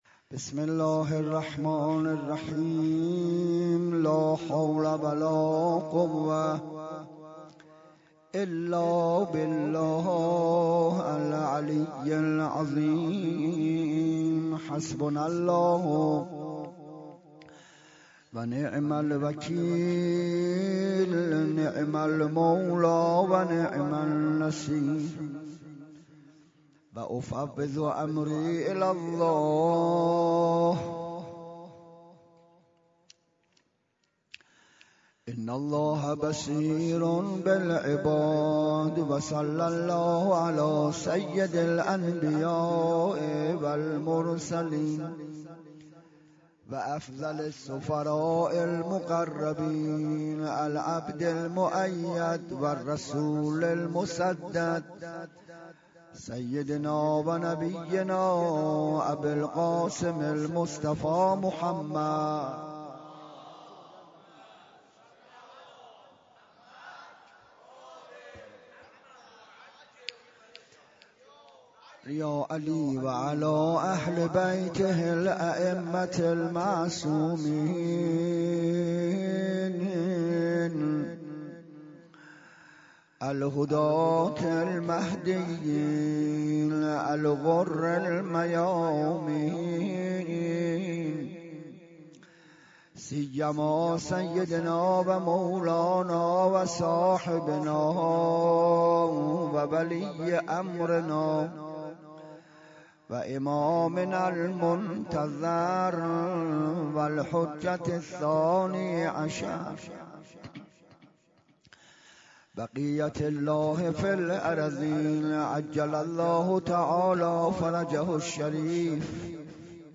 16 محرم 97 - حسینیه کربلایی های یزد - سخنرانی